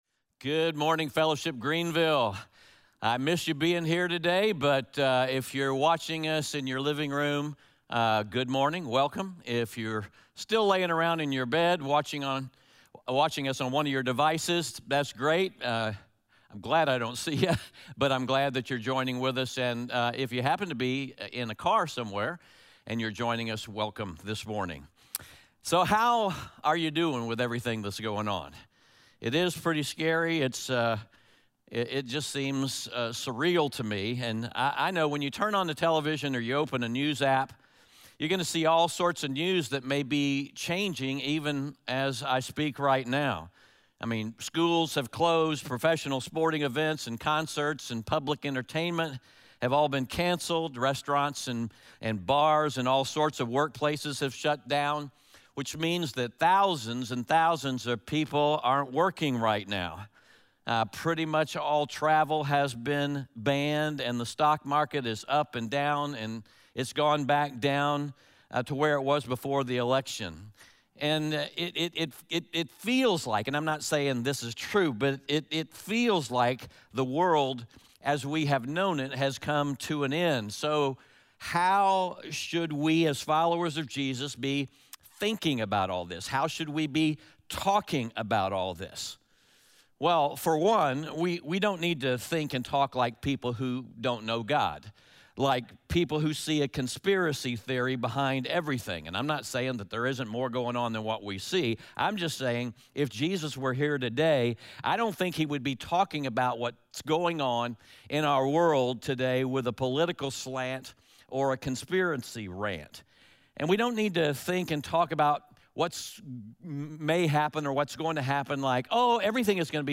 Discussion questions mentioned in the sermon today.